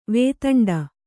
♪ vētaṇḍa